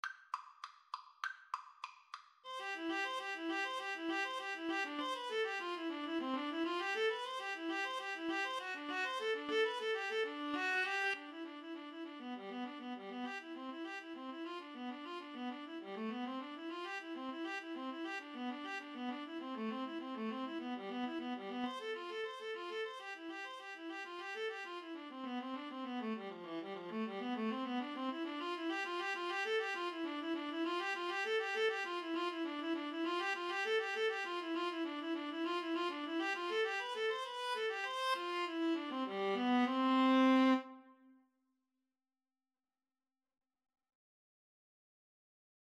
C major (Sounding Pitch) (View more C major Music for Viola Duet )
Allegro =200 (View more music marked Allegro)
Classical (View more Classical Viola Duet Music)